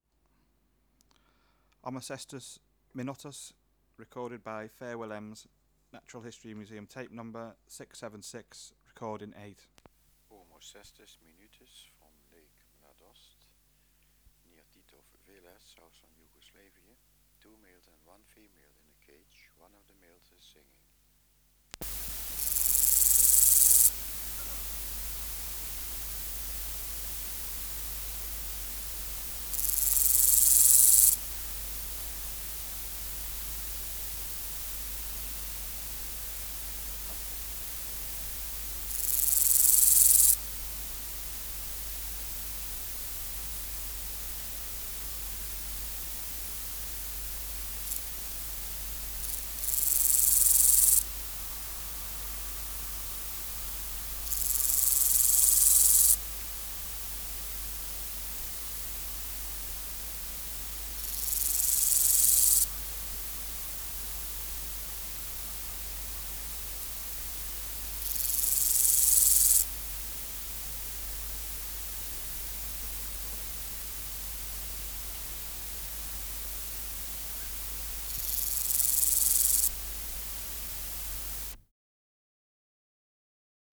568:8 Omocestus minutus (676r8) | BioAcoustica
Species: Omocestus (Omocestus) minutus